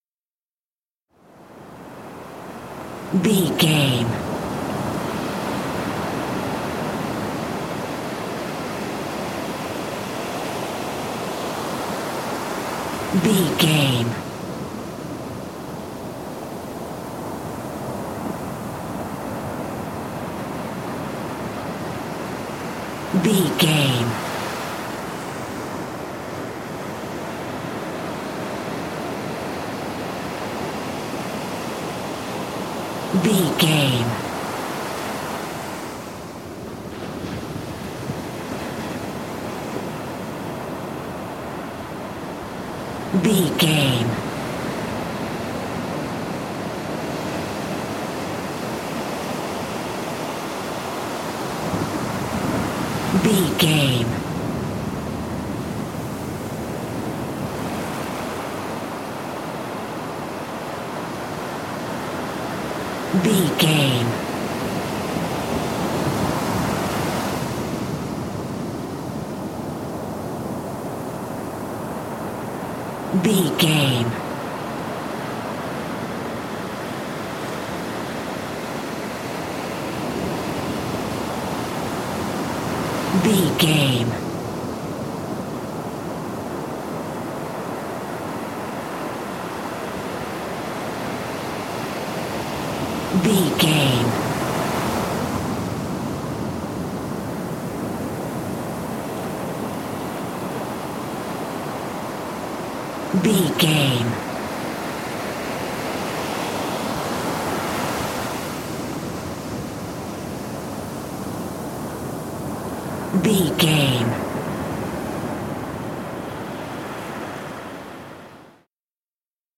Beach ocean waves
Sound Effects
SEAMLESS LOOPING?
calm
hypnotic
peaceful
repetitive
ambience